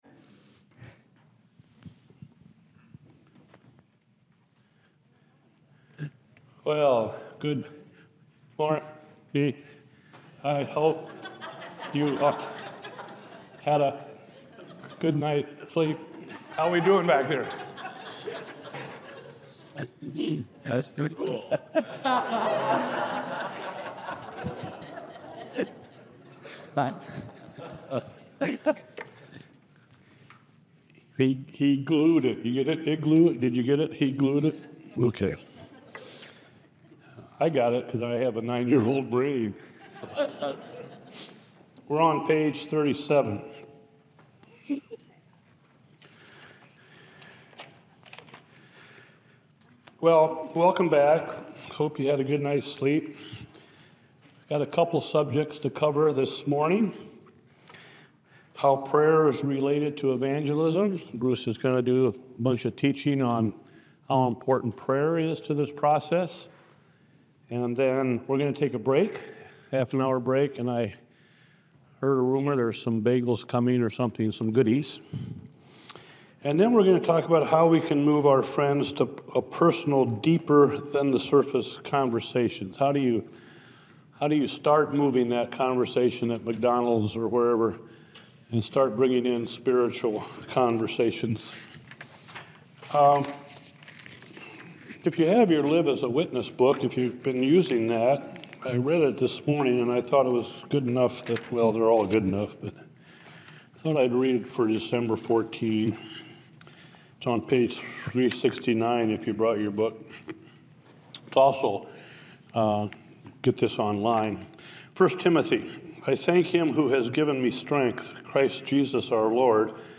Seminar recordings from Evangelism Shift.